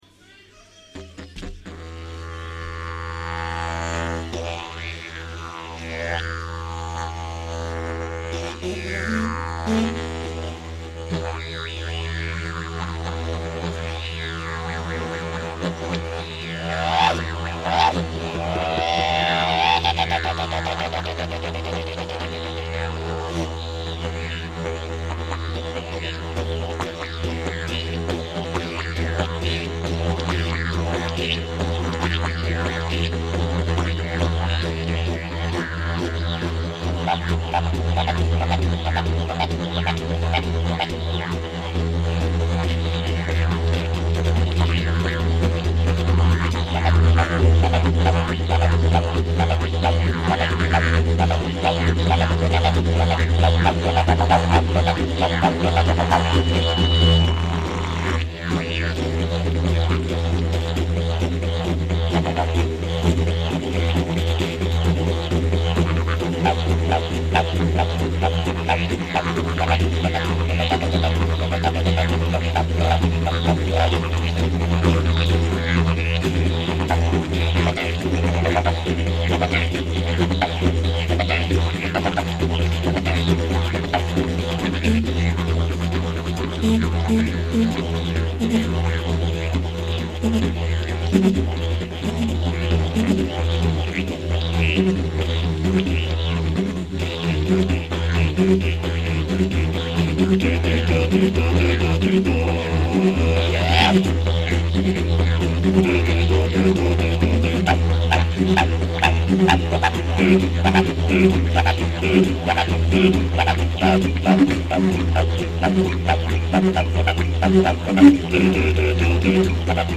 deuxième petit morceau du concert.